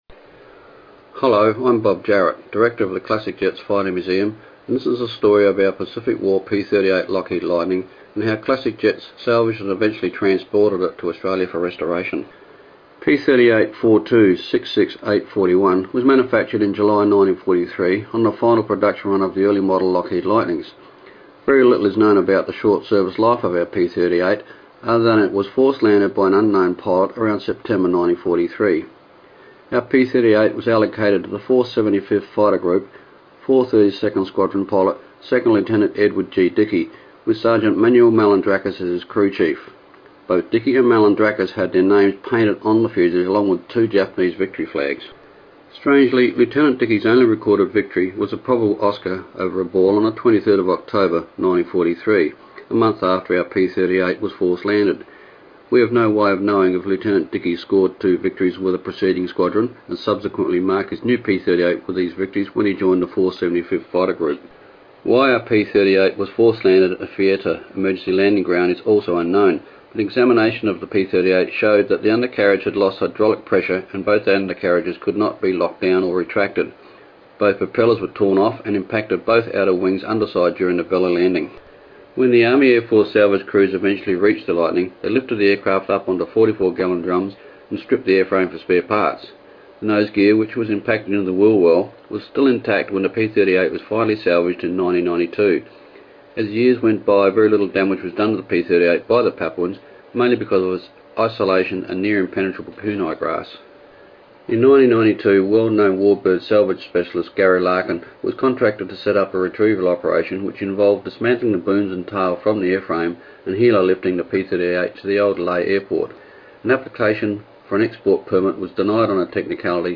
MP3 Interview